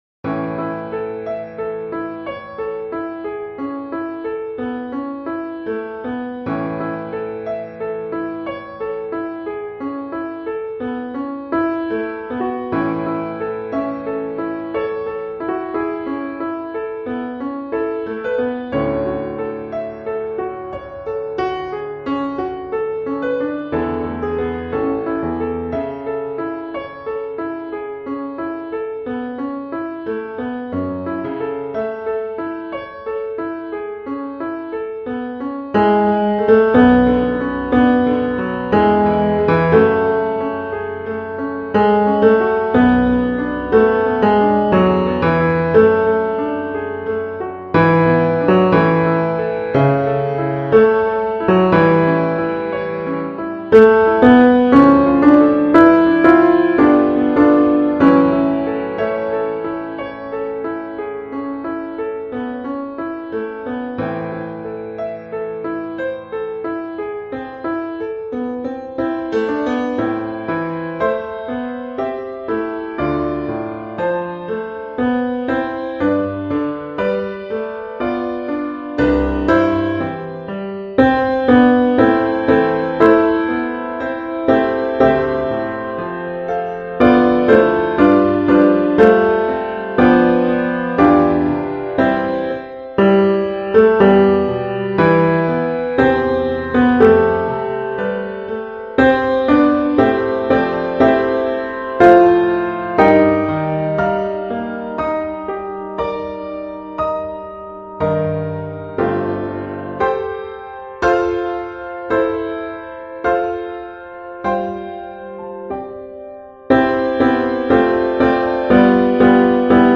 Let Me Hide Myself in Thee – Tenor